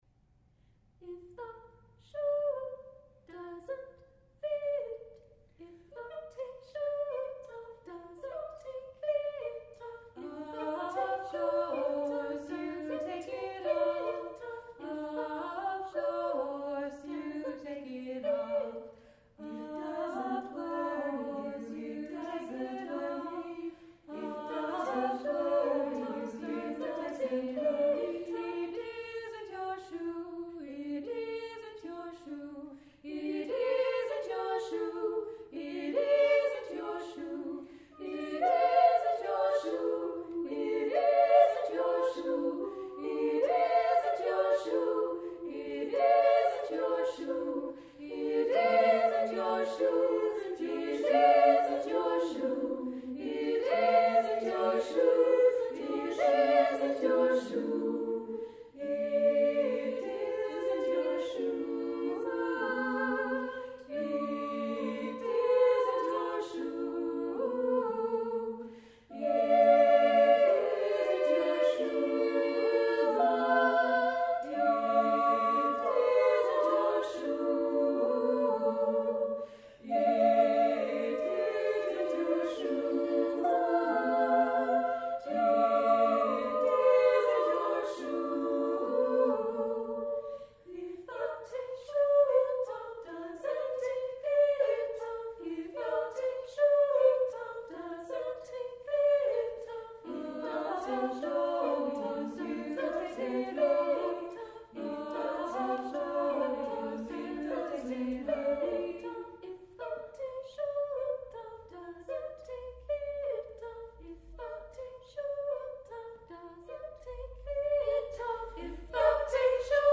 Naomi Shihab Nye’s poem, set to music, for SSAA
I love it, and it was short enough to have fun composing a short rhythmic choral piece based on the words.
Here is the audio version of the song with 9 singers, recorded at the Conseil des Arts de Montréal on October 27th, 2018.
shoe_choir.mp3